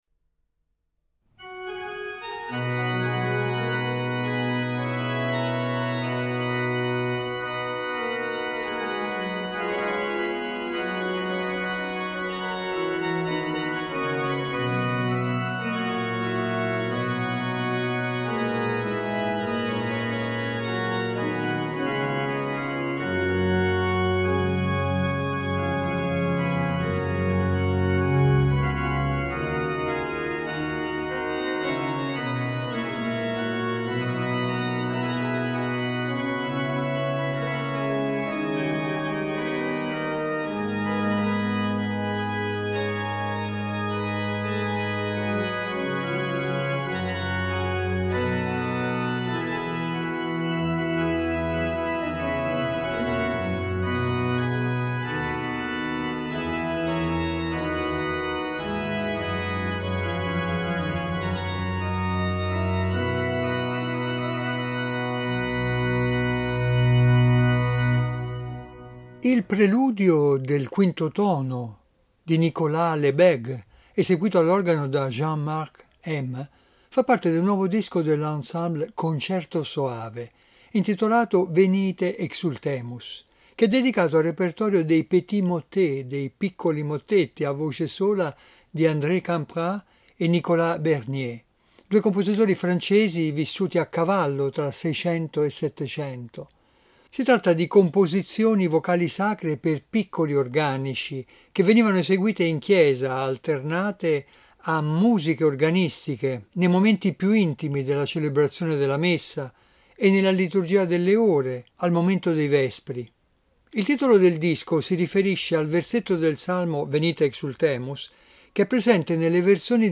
I petits motets a voce sola di Bernier e Campra